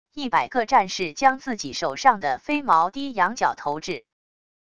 100个战士将自己手上的飞矛低仰角投掷wav音频